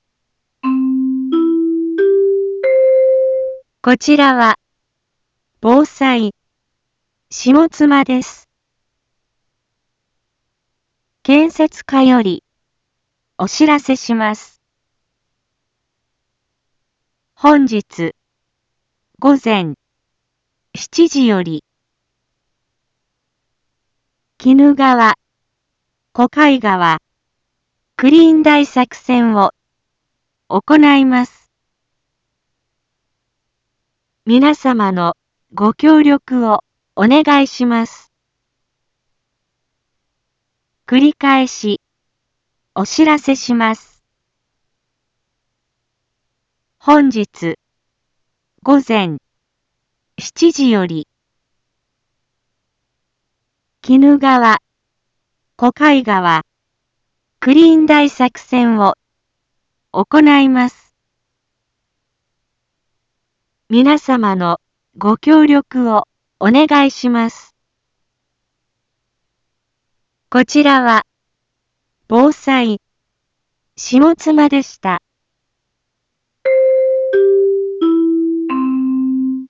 一般放送情報
BO-SAI navi Back Home 一般放送情報 音声放送 再生 一般放送情報 登録日時：2023-07-08 06:31:23 タイトル：鬼怒川・小貝川クリーン大作戦のお知らせ インフォメーション：こちらは、ぼうさい、しもつまです。